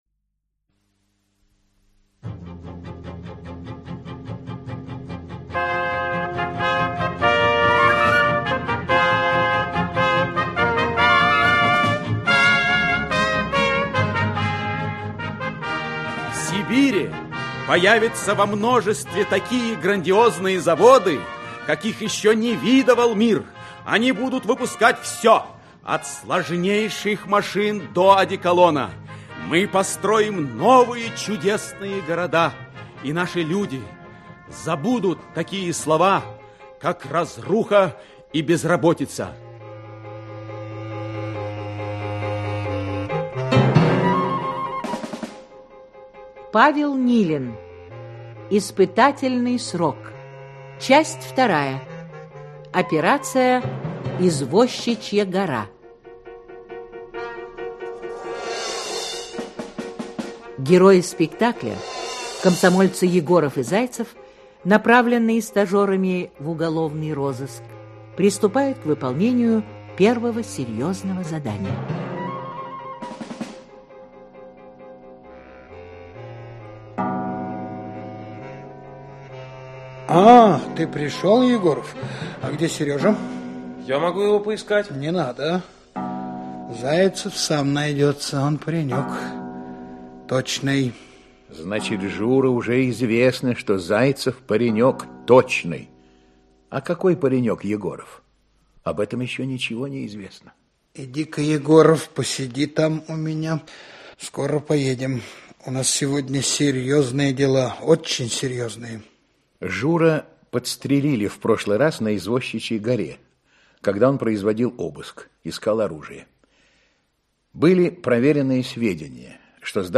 Аудиокнига Испытательный срок. Часть 2. «Извозчичья гора» | Библиотека аудиокниг
«Извозчичья гора» Автор Павел Филиппович Нилин Читает аудиокнигу Актерский коллектив.